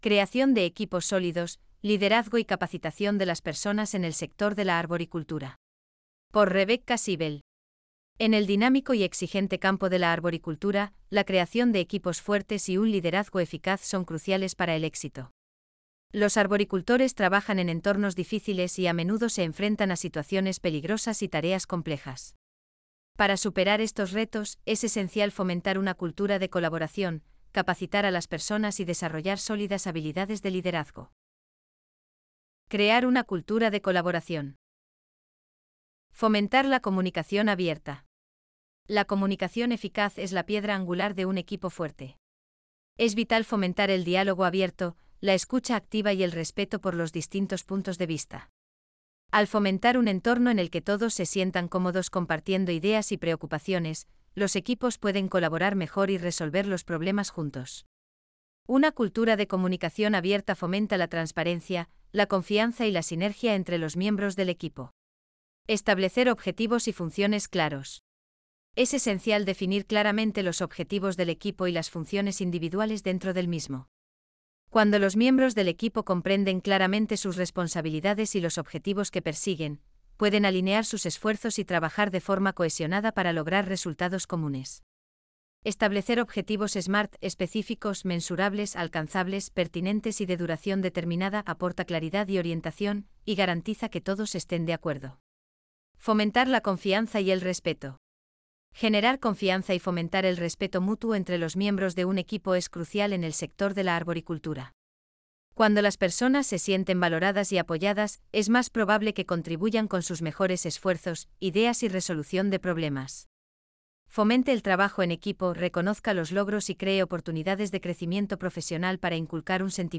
Este es nuestro intento de convertir las historias en audio español usando Inteligencia Artificial.